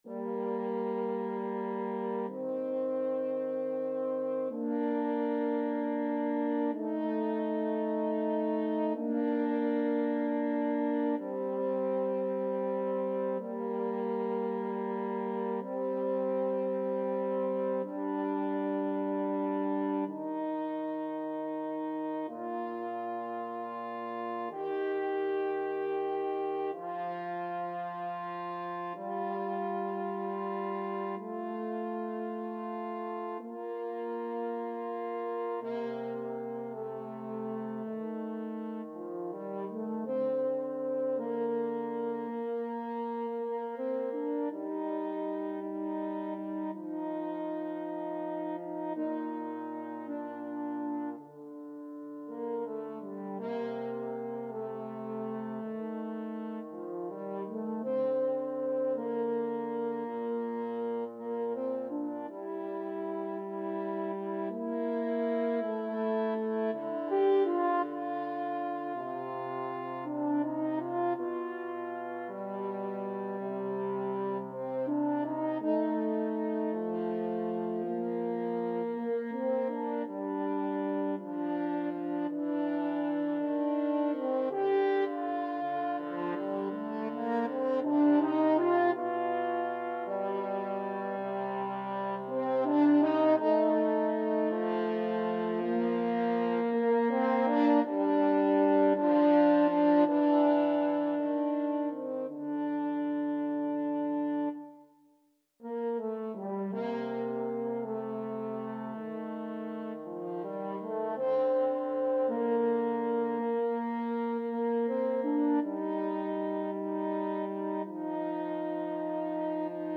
French Horn 1French Horn 2
12/8 (View more 12/8 Music)
Andante cantabile, con alcuna licenza (. = 54)
Classical (View more Classical French Horn Duet Music)